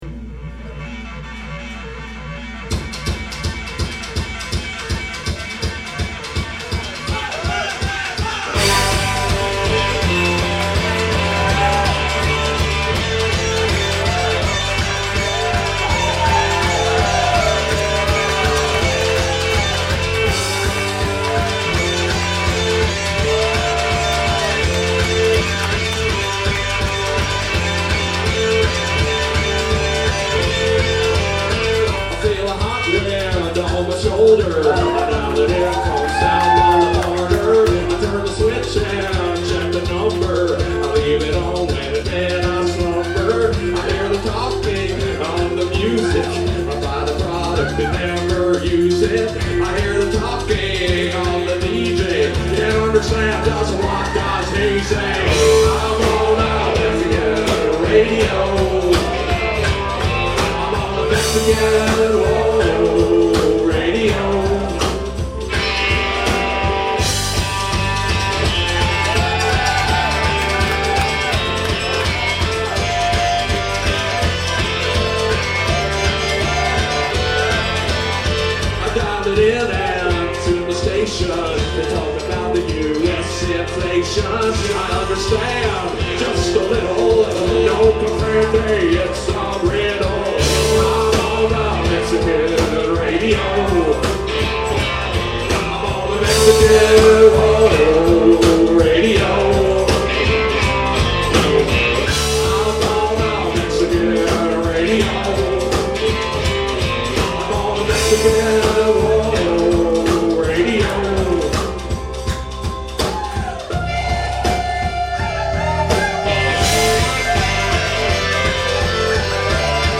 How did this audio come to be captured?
August 5, 2000 show in San Diego: though somewhat lo-fi, cover